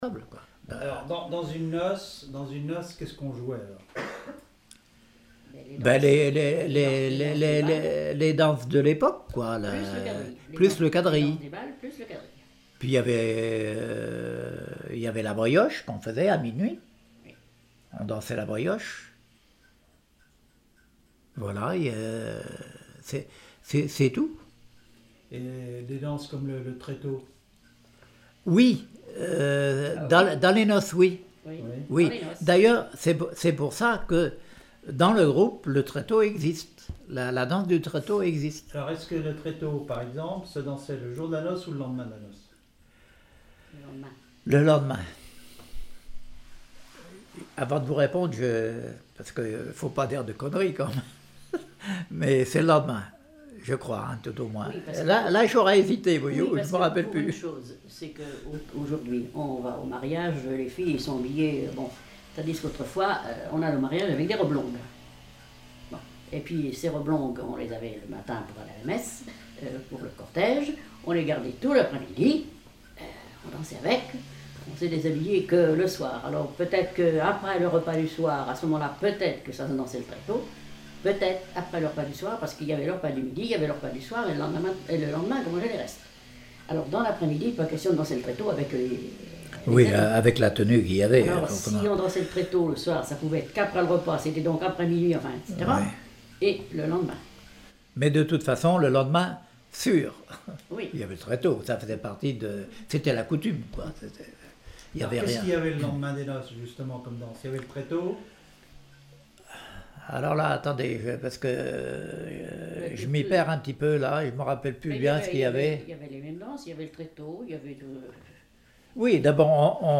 Témoignage comme joueur de clarinette